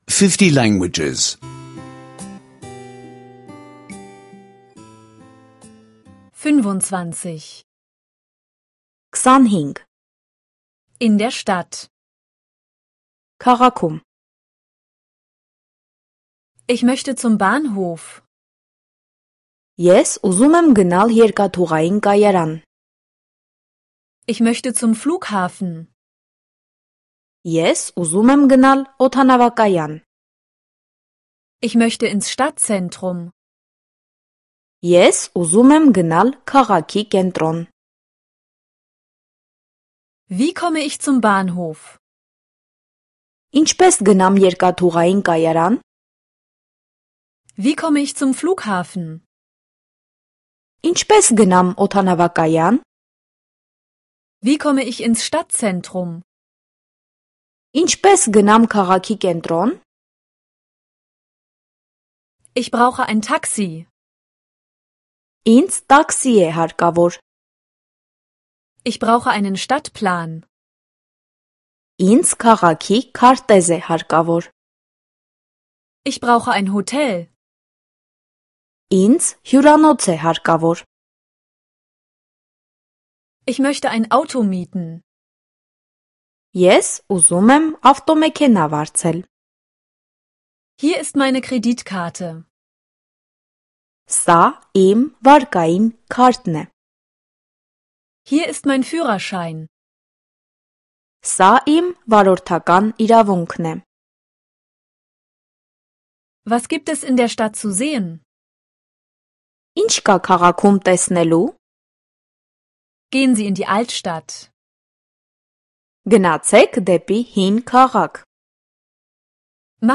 Armenische Audio-Lektionen, die Sie kostenlos online anhören können.